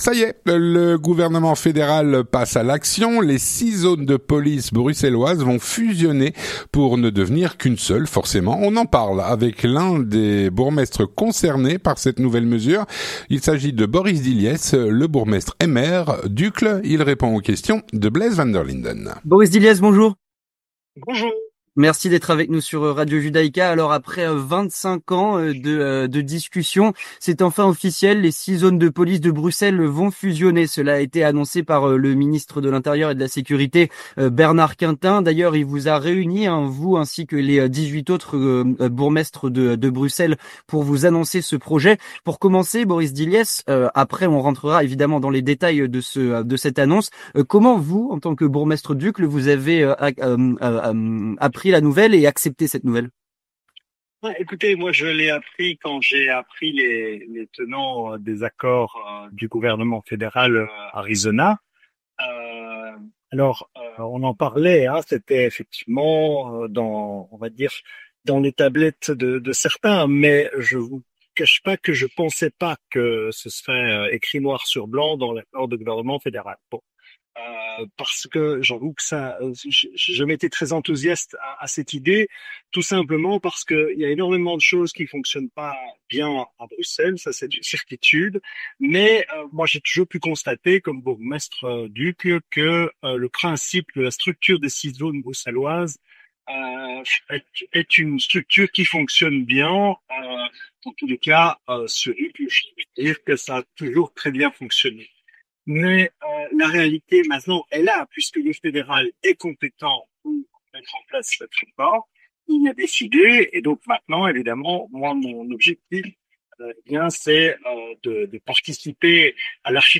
3. L'entretien du 18h
On en parle avec l’un des bourgmestres concernés, Boris Dilliès, qui dirige la commune d’Uccle.